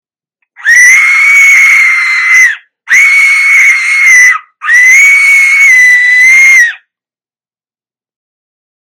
Large Aztec Death whistle
The Aztec Death whistle, hand tuned to produce the most frightening scariest sound.
The Aztec Death whistle is a hand crafted musical instrument producing the loudest, scariest, terrifying sound around.